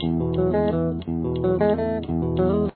This is a challenging riff at 188 bpm.